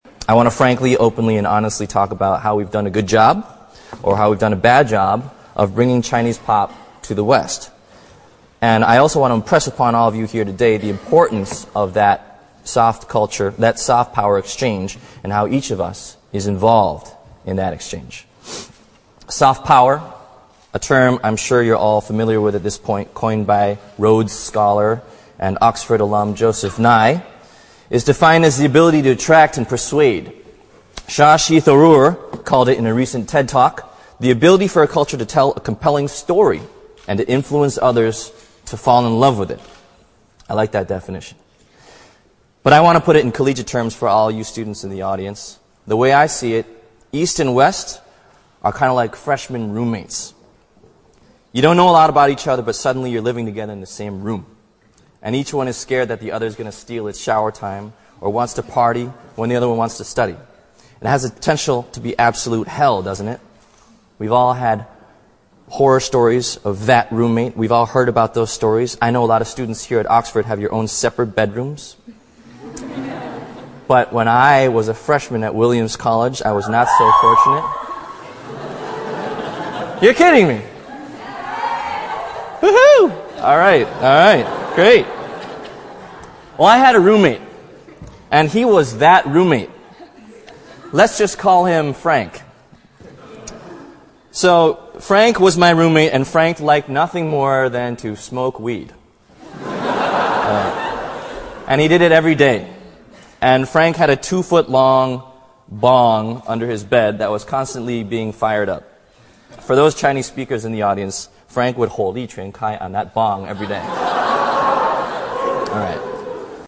王力宏牛津大学演讲 第2期 听力文件下载—在线英语听力室
在线英语听力室王力宏牛津大学演讲 第2期的听力文件下载,哈佛牛津名人名校演讲包含中英字幕音频MP3文件，里面的英语演讲，发音地道，慷慨激昂，名人的效应就是激励他人努力取得成功。